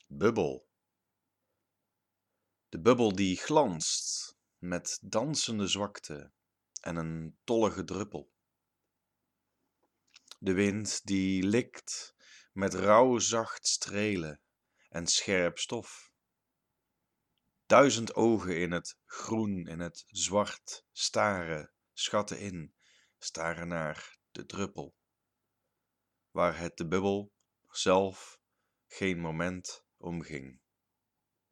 Poëzie